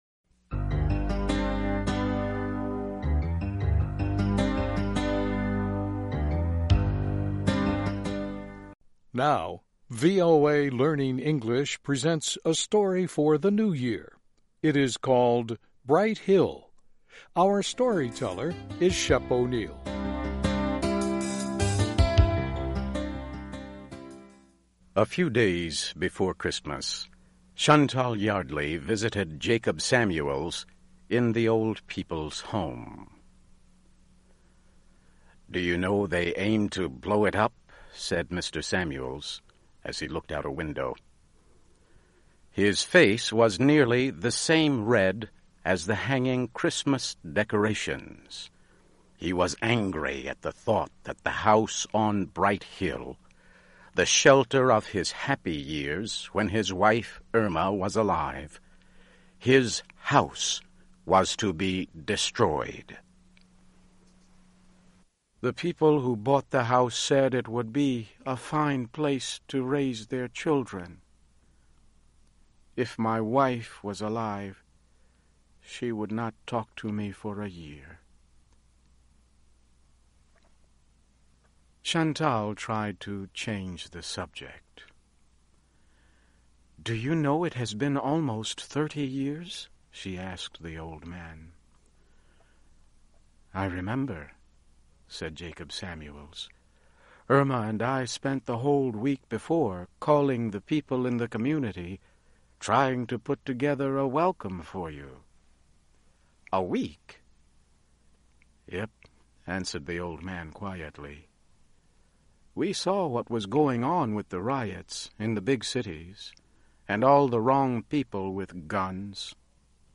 Learn English as you read and listen to a weekly show with short stories by famous American authors. Adaptations are written at the intermediate and upper-beginner level and are read one-third slower than regular VOA English.